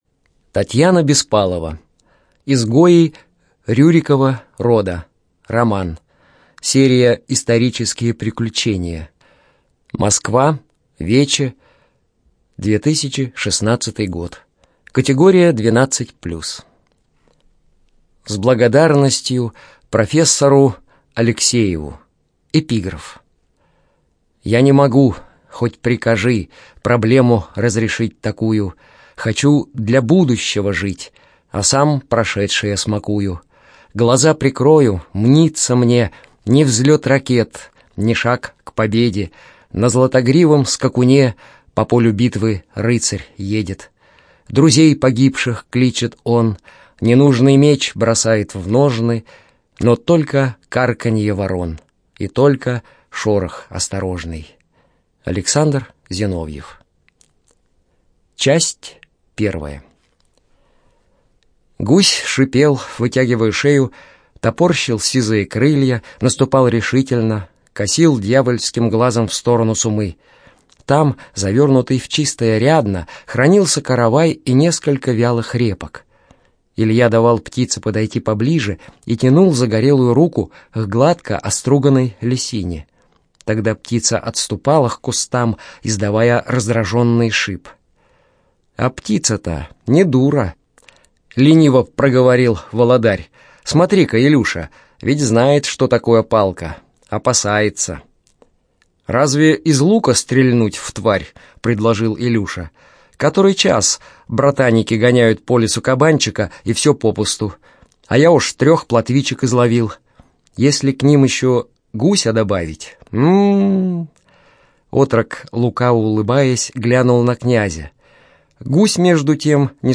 ЖанрПриключения, Историческая проза
Студия звукозаписиЛогосвос